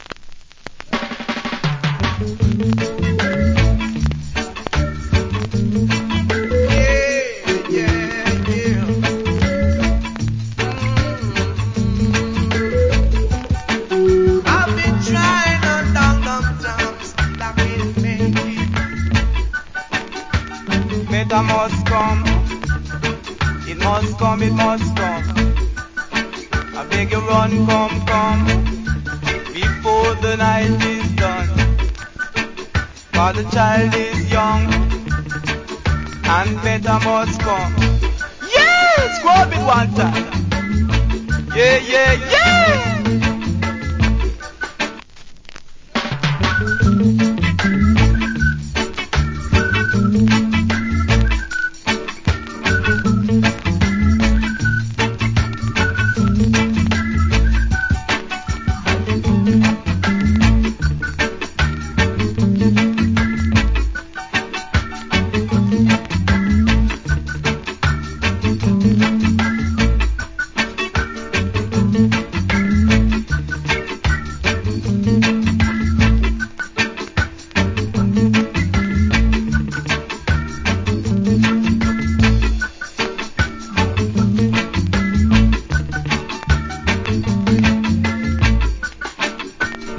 コメント Good DJ.